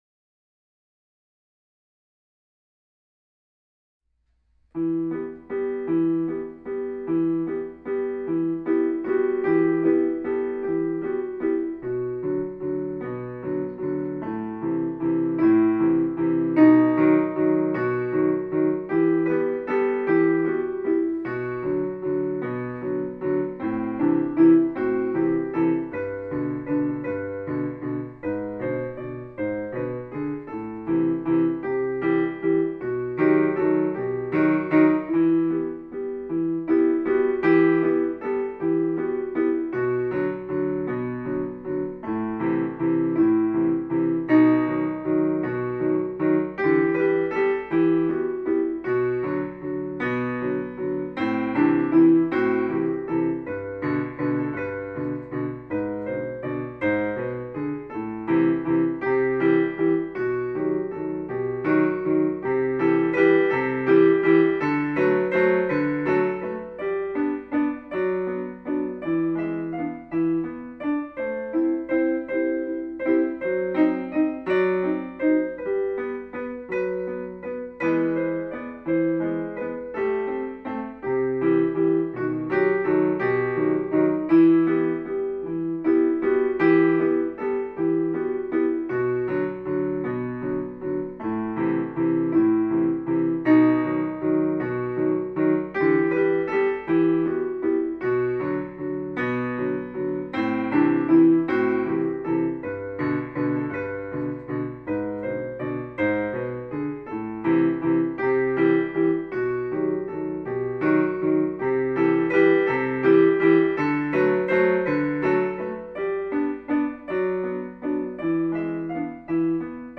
vals
new-waltz-3-06-06.mp3